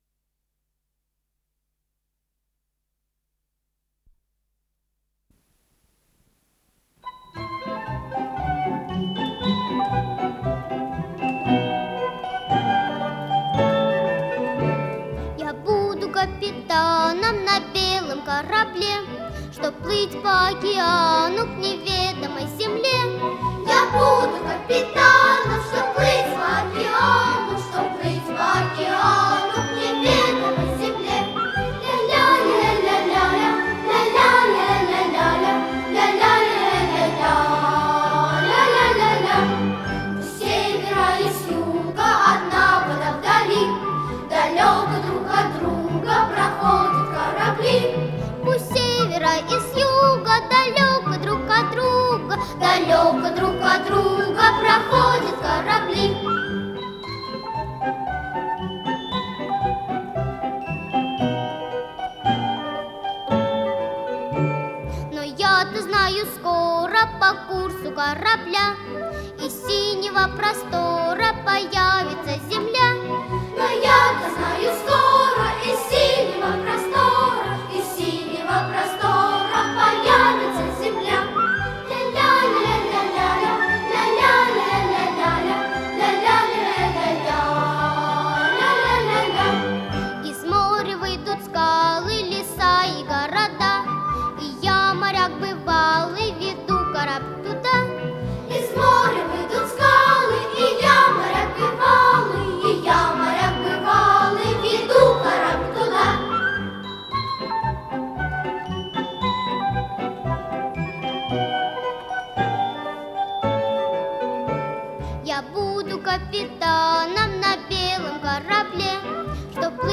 АккомпаниментИнструментальный ансамбль
ВариантДубль моно